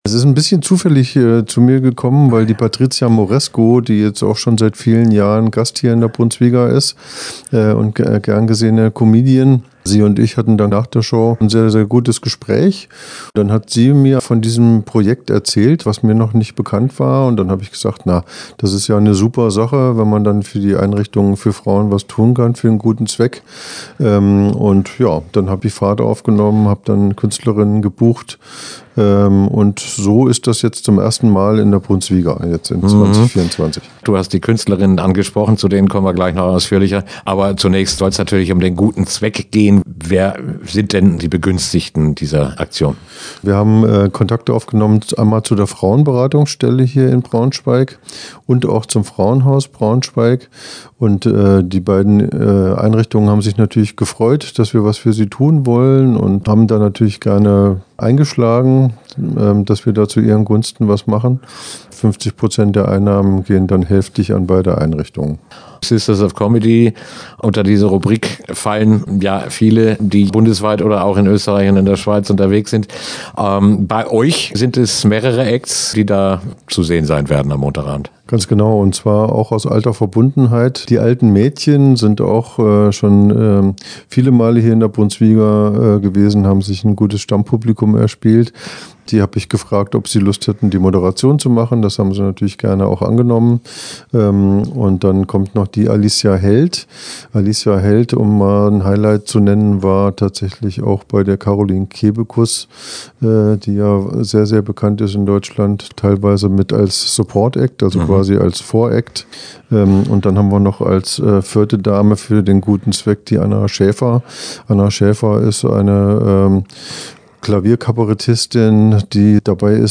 Interview-Sisters-of-Comedy.mp3